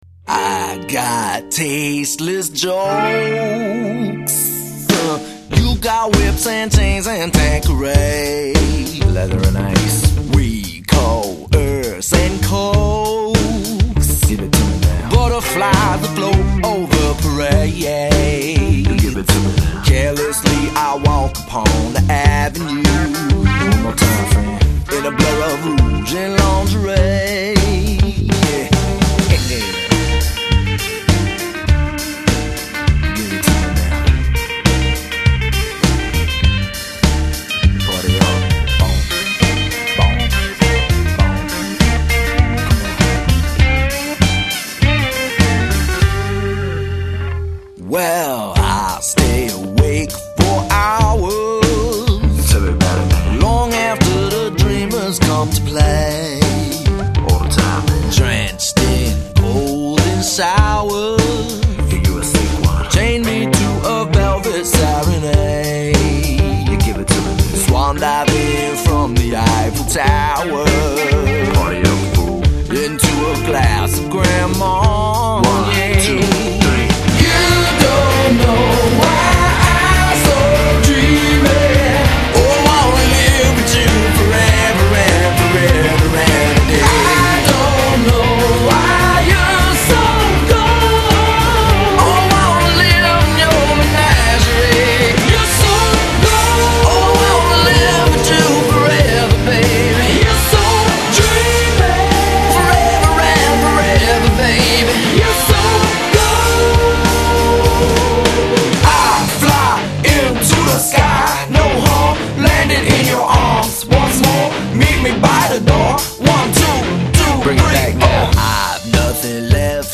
Genre: Alt.Rock.